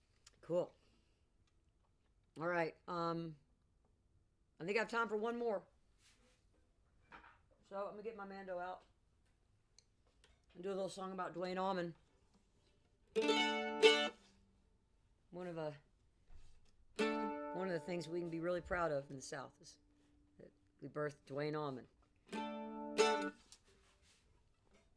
(audio from a portion of the program captured from webcast)
14. talking with the crowd (amy ray) (0:24)